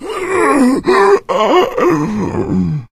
fracture_attack_4.ogg